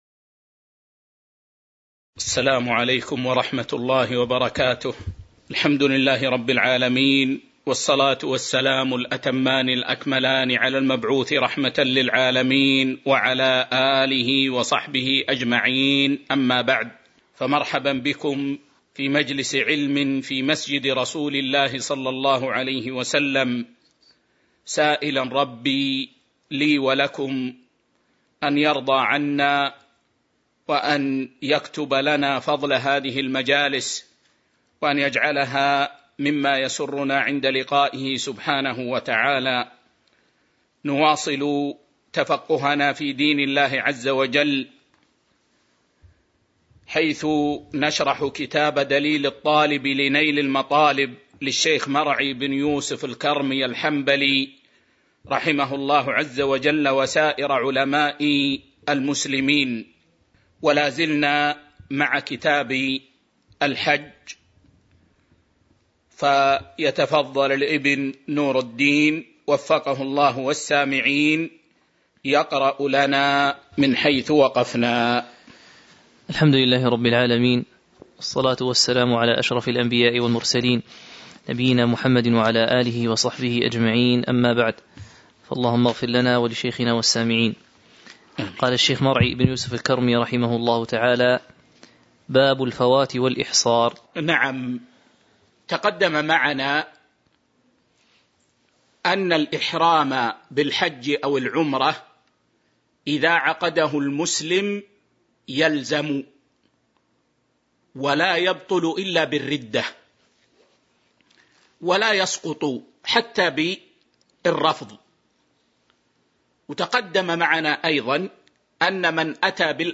تاريخ النشر ٢ جمادى الأولى ١٤٤٢ هـ المكان: المسجد النبوي الشيخ